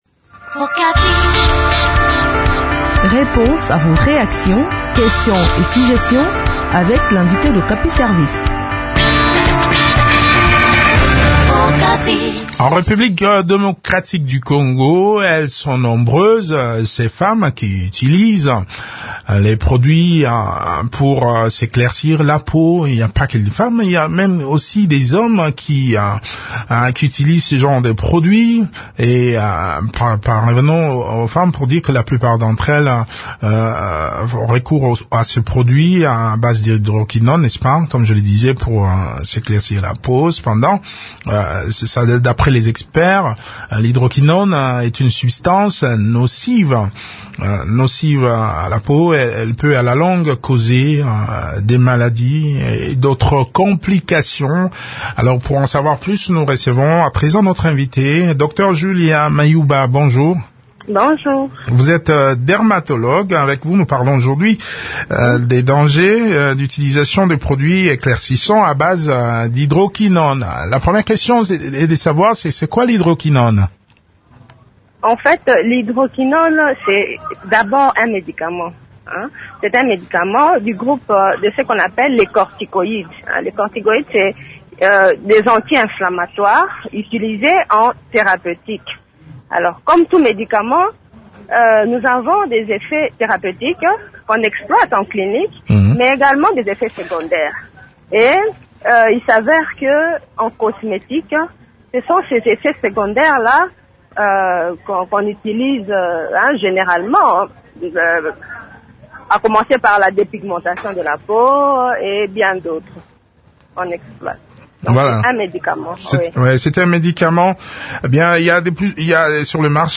discute de ce sujet avec la dermatologue